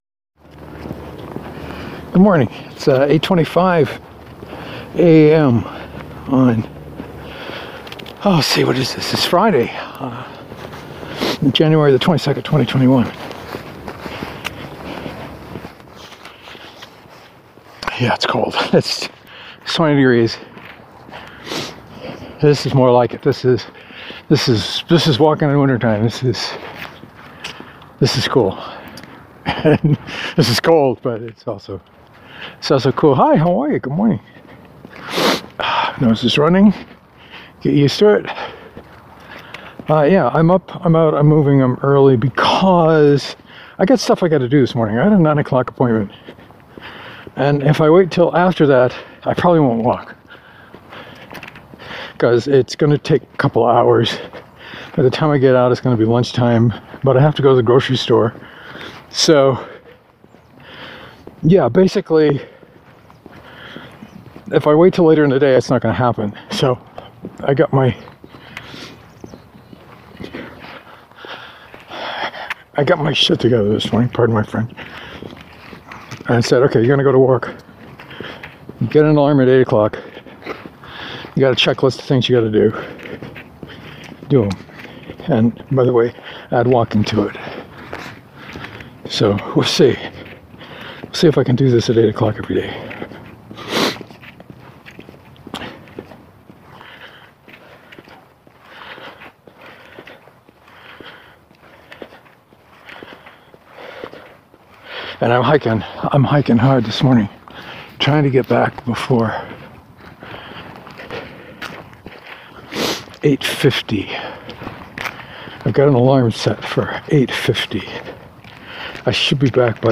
It felt good to be out early, even if it was pretty chilly. I talked about Factorio (again) and a video workshop I attended yesterday.